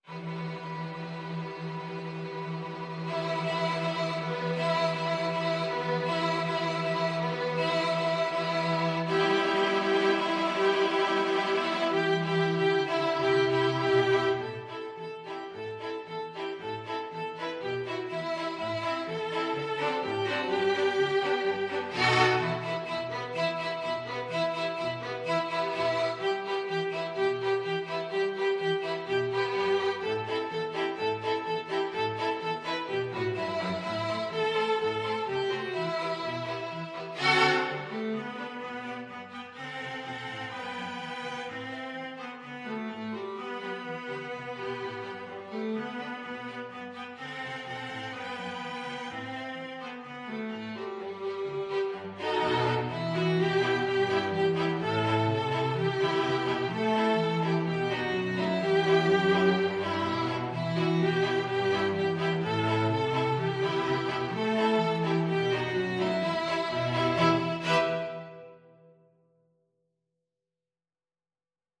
Violin 1Violin 2ViolaCello
E minor (Sounding Pitch) (View more E minor Music for String Quartet )
Andante =c.80
2/2 (View more 2/2 Music)
String Quartet  (View more Intermediate String Quartet Music)
Israeli